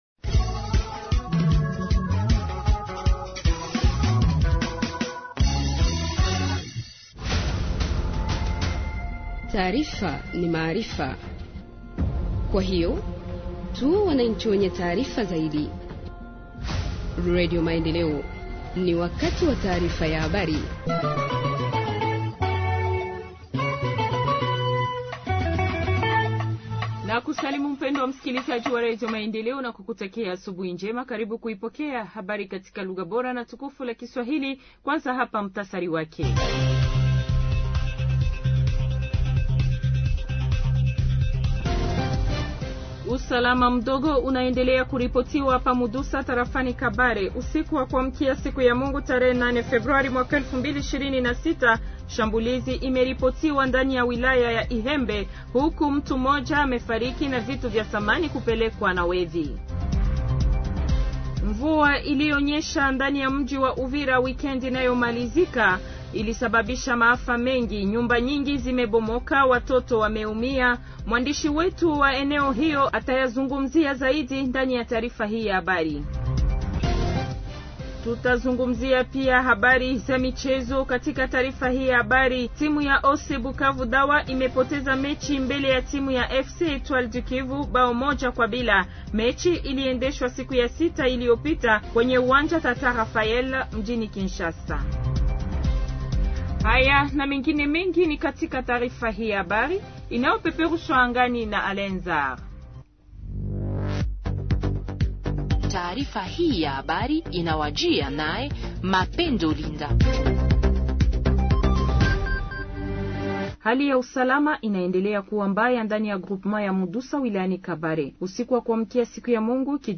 Journal en Swahili du 10 Février 2026 – Radio Maendeleo